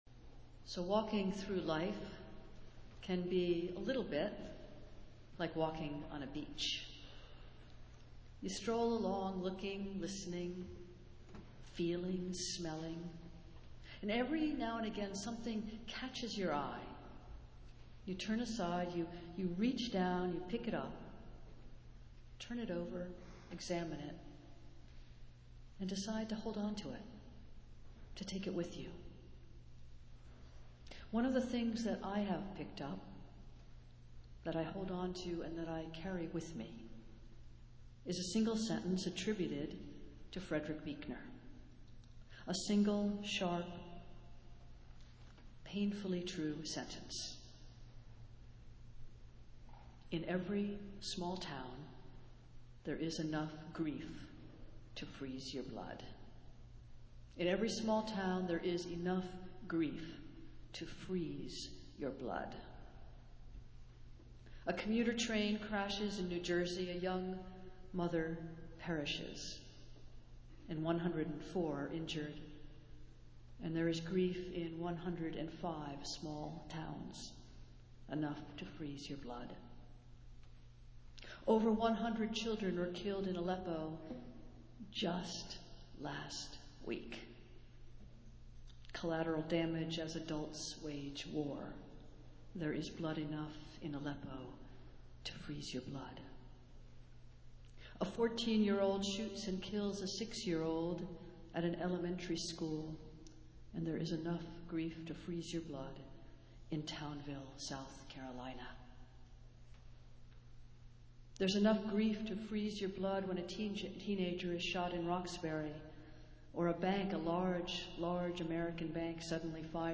Festival Worship - World Communion Sunday